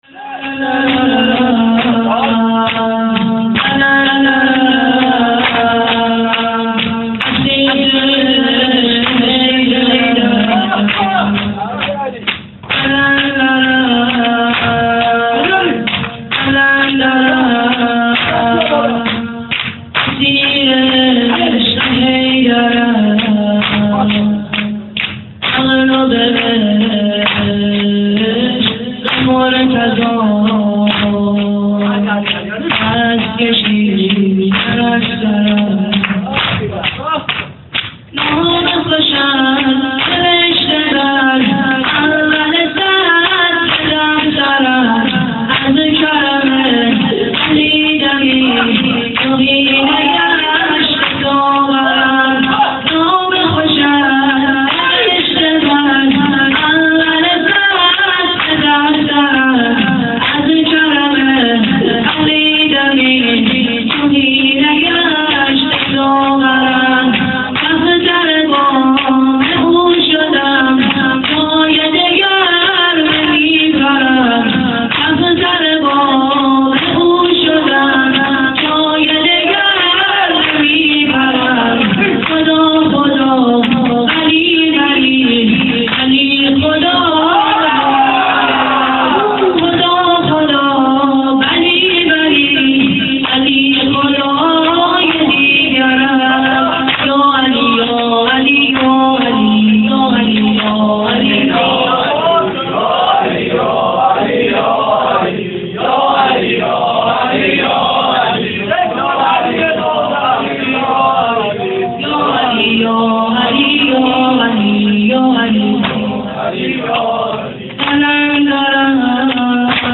چهار ضرب - قلندرم قلندرم
کیفیت ضعیف